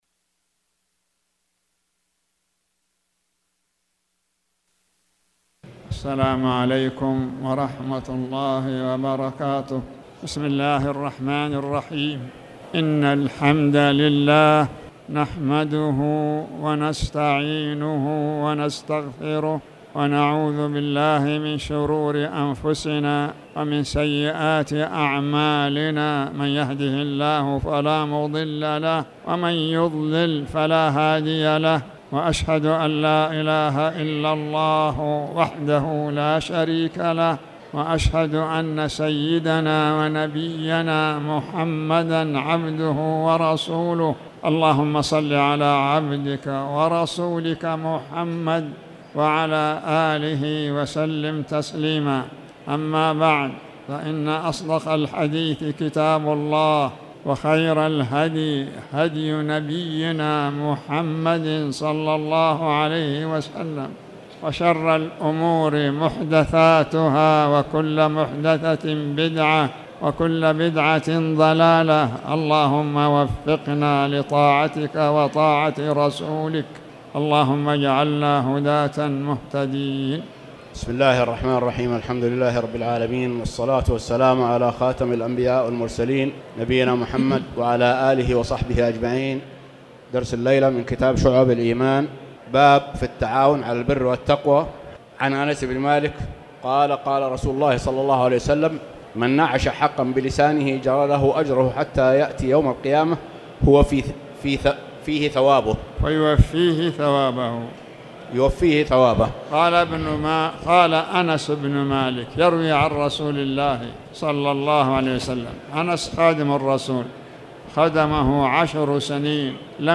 تاريخ النشر ٤ صفر ١٤٣٩ هـ المكان: المسجد الحرام الشيخ